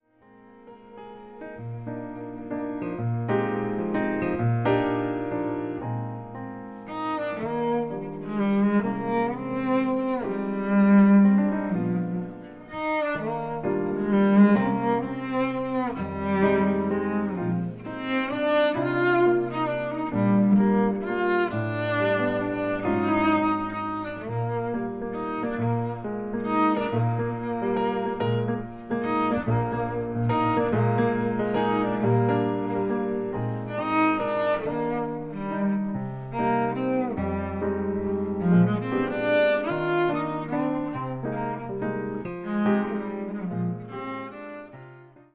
チェロのたおやかな音色による実直な美の結晶であること。
チェロ
ピアノ